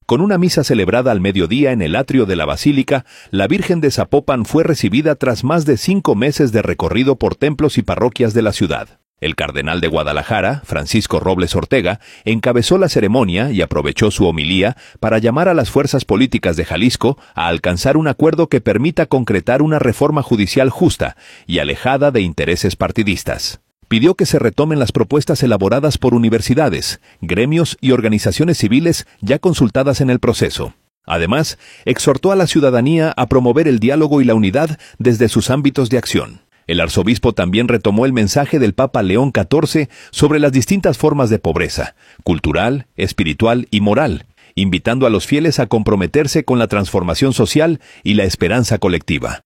audio Con una misa celebrada al mediodía en el atrio de la Basílica, la Virgen de Zapopan fue recibida tras más de cinco meses de recorrido por templos y parroquias de la ciudad. El cardenal de Guadalajara, Francisco Robles Ortega, encabezó la ceremonia y aprovechó su homilía para llamar a las fuerzas políticas de Jalisco a alcanzar un acuerdo que permita concretar una reforma judicial justa y alejada de intereses partidistas. Pidió que se retomen las propuestas elaboradas por universidades, gremios y organizaciones civiles ya consultadas en el proceso. Además, exhortó a la ciudadanía a promover el diálogo y la unidad desde sus ámbitos de acción.